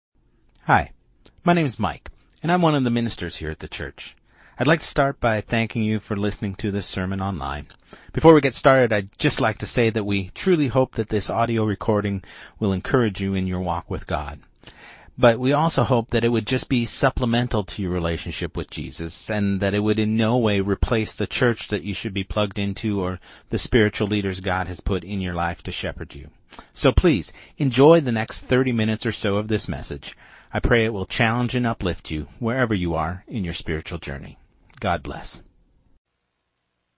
Sermon2025-08-03